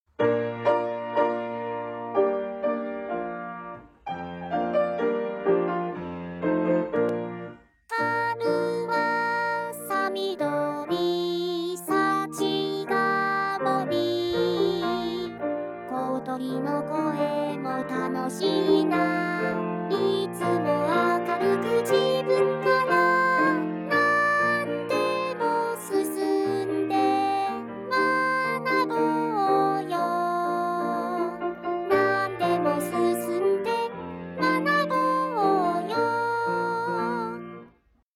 小学校の校歌をボカロに歌わせました。
ピアノ伴奏ありVer